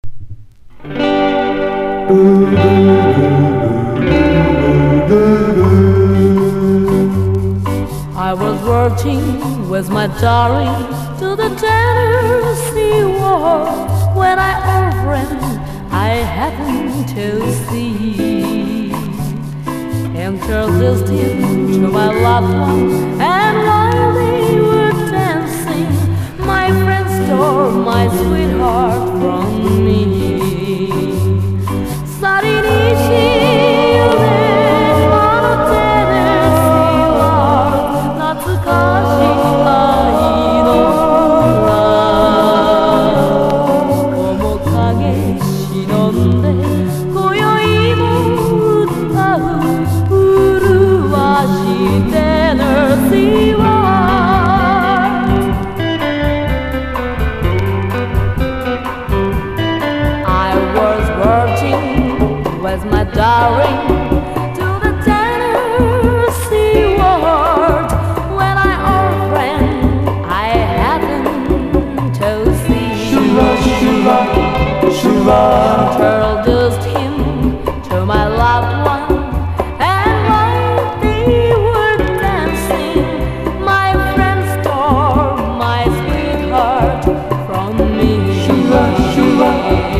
ロックンロールでフロア向けなGSナンバー